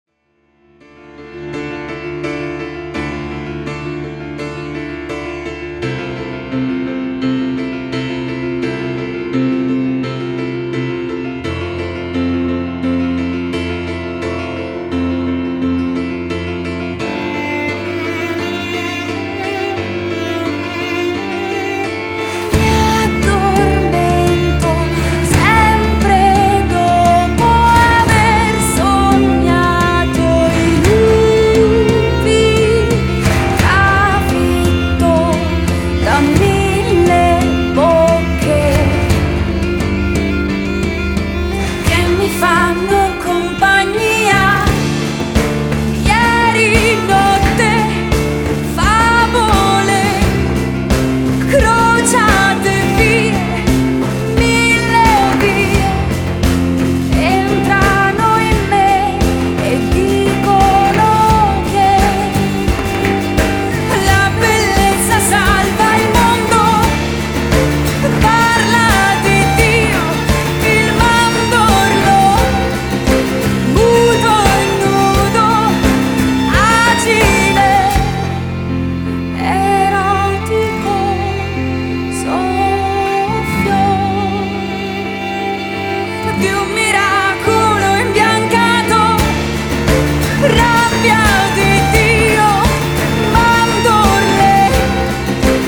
地中海の叙情と哀愁溢れる感動作キターー！
guitars
vocals, cello
violin
piano
bass
drums